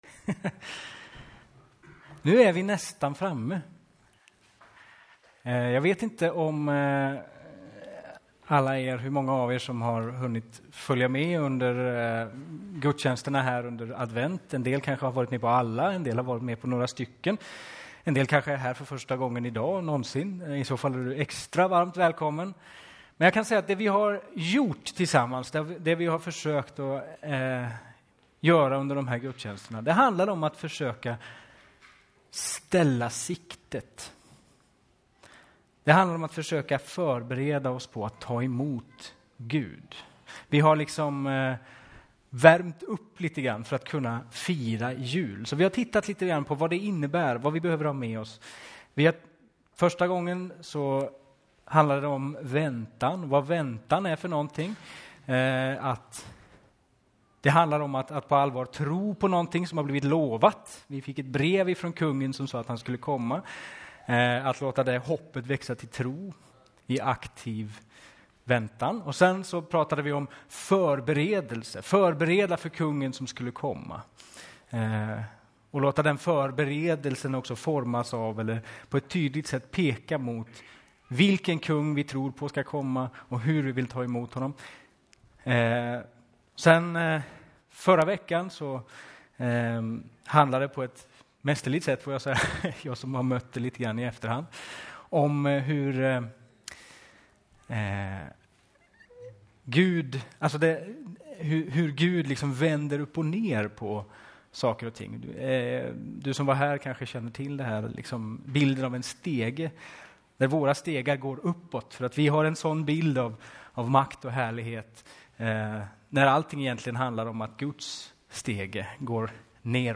4:e söndagen i advent.